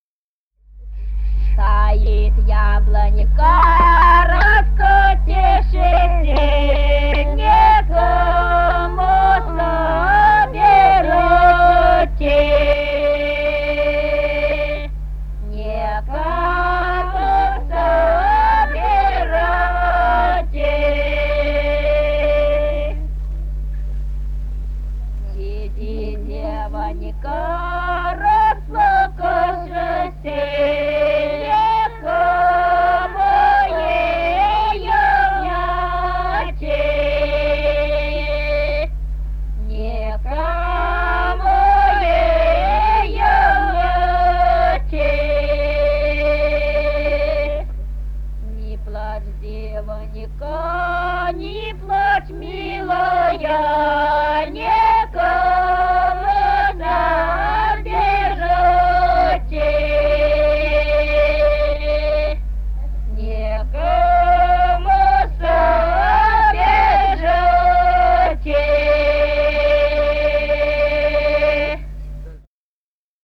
Русские народные песни Красноярского края.